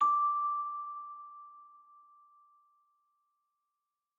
celesta1_3.ogg